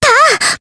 Jane-Vox_Attack4_jp.wav